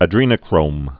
(ə-drēnō-krōm, -nə-)